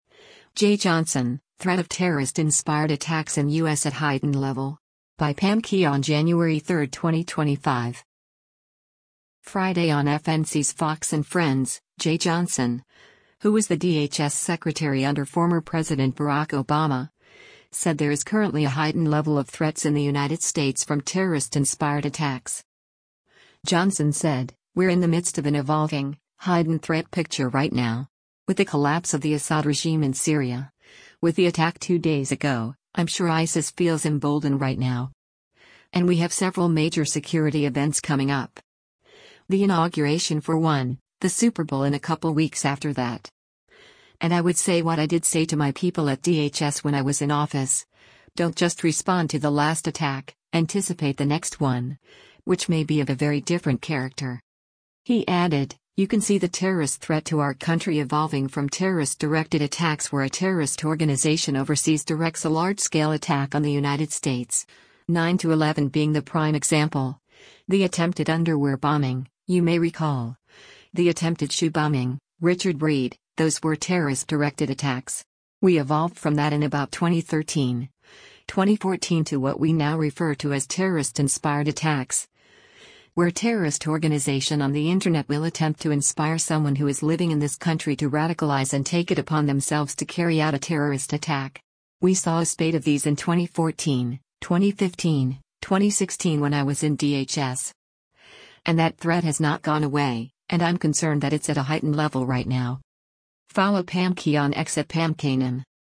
Friday on FNC’s “Fox & Friends,” Jeh Johnson, who was the DHS secretary under former President Barack Obama, said there is currently a “heightened level” of threats in the United States from “terrorist-inspired attacks.”